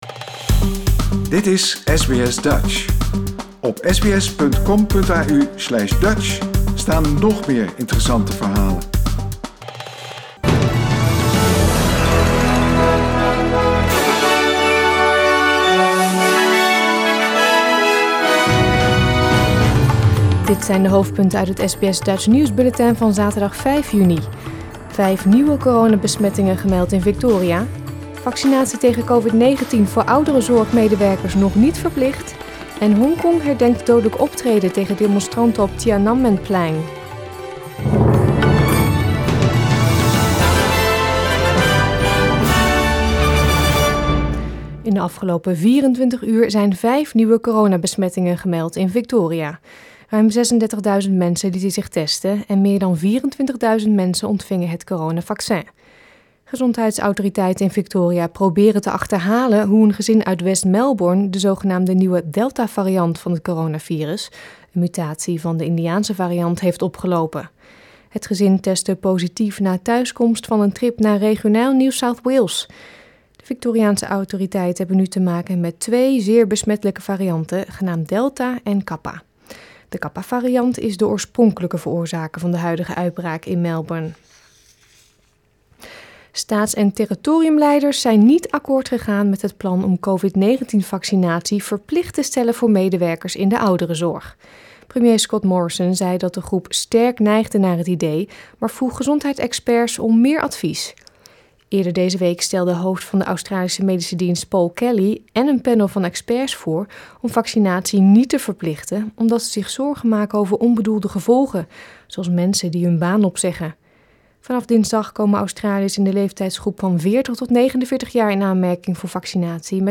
Nederlands/Australisch SBS Dutch nieuwsbulletin van zaterdag 5 juni 2021